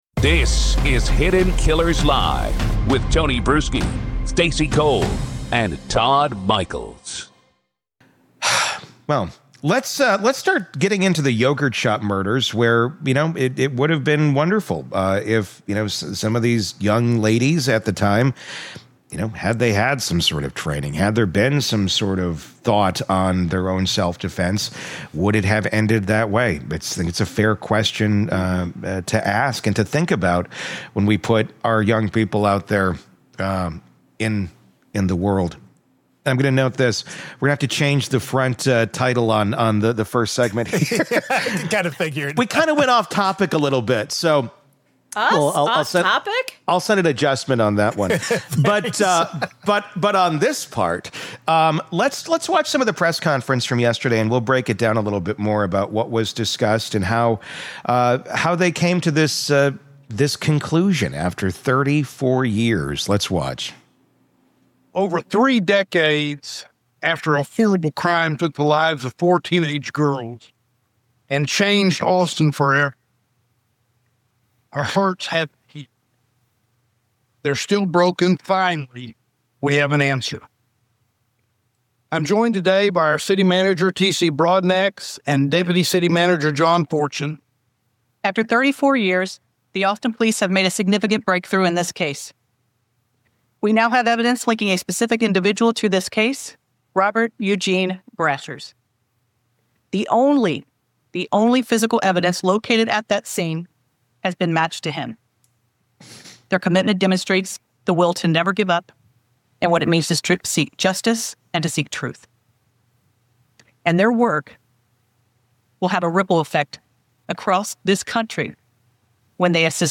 In this powerful segment of Hidden Killers Live , we continue watching the APD press conference and confront one of the most devastating truths about this case: the state prosecuted and convicted the wrong men .
We play back the portion of the press conference where Travis County DA Jose Garza publicly acknowledges their innocence —and we react to the gravity of what that admission means.